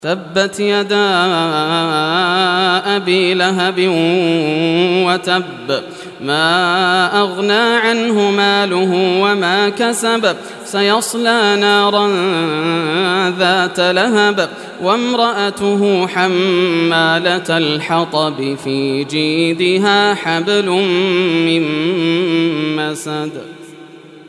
Surah Al-Masad Recitation by Yasser al Dosari
Surah Al-Masad, listen or play online mp3 tilawat / recitation in Arabic in the beautiful voice of Sheikh Yasser al Dosari.